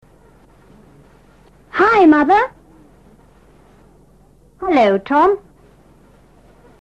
Ahora escucha esta mini-conversación entre Tom y su madre.